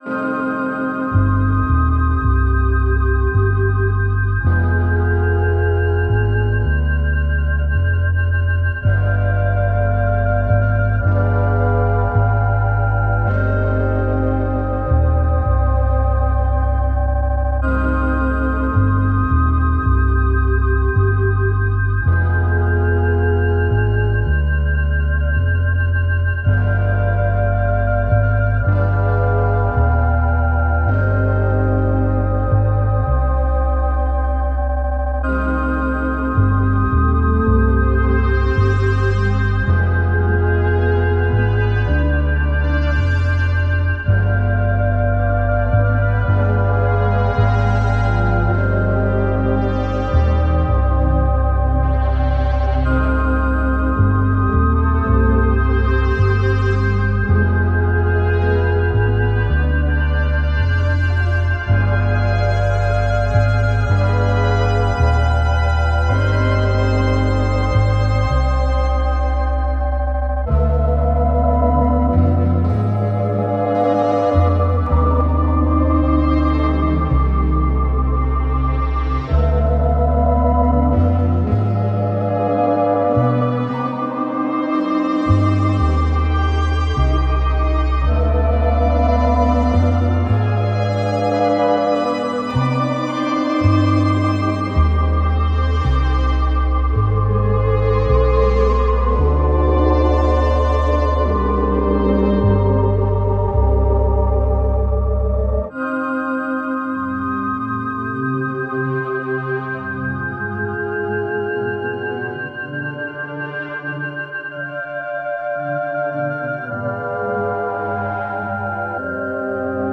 Spooky organ theme and bass with quirky synth textures.